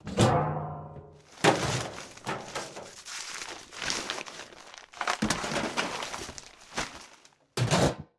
garbage_can_2.ogg